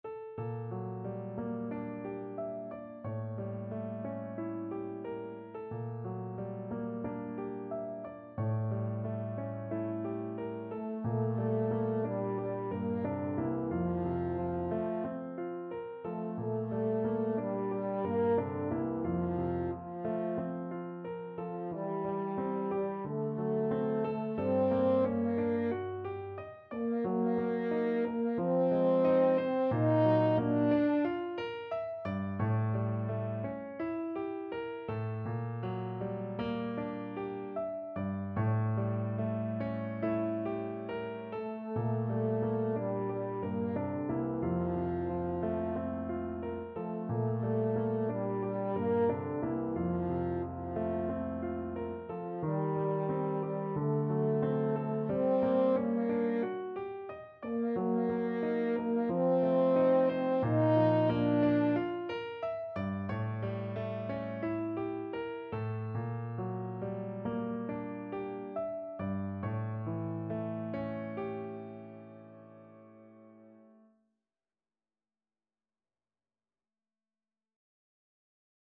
Time Signature: 2/4
Langsam, zart